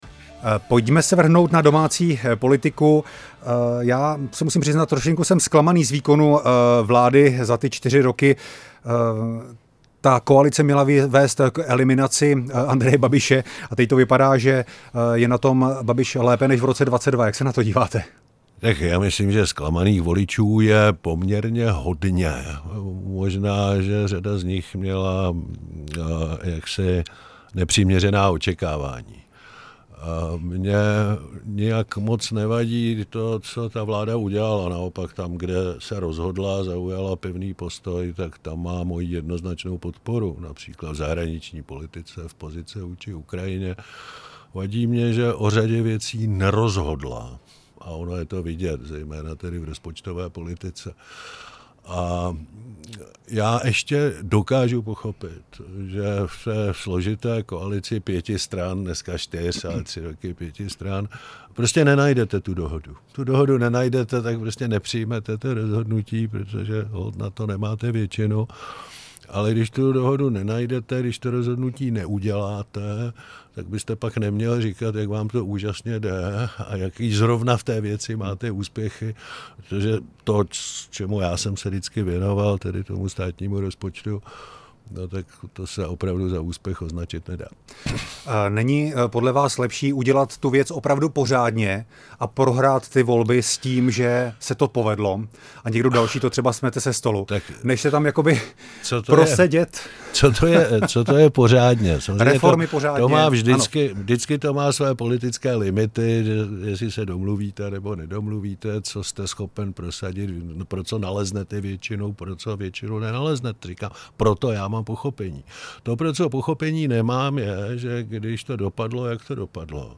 Druhá část rozhovoru s exministrem financí Miroslavem Kalouskem